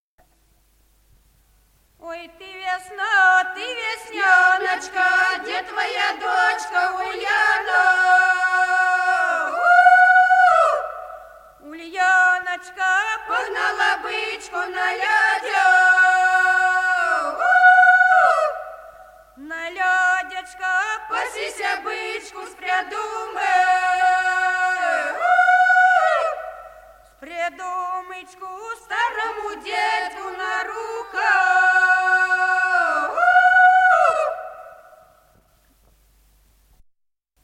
Записано в Москве, декабрь 1966 г., с. Курковичи.